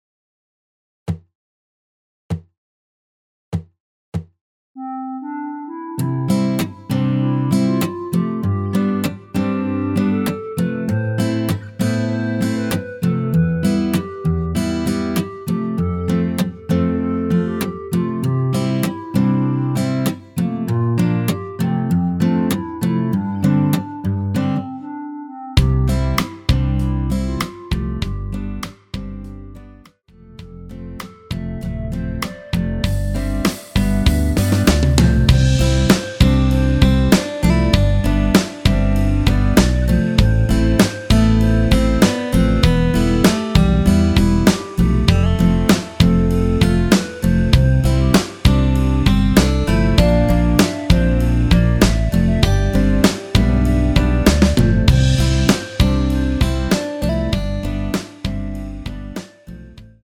원키에서(-2)내린 멜로디 포함된 MR이며 노래가 바로 시작 하는 곡이라 전주 만들어 놓았습니다.
멜로디 MR이라고 합니다.
앞부분30초, 뒷부분30초씩 편집해서 올려 드리고 있습니다.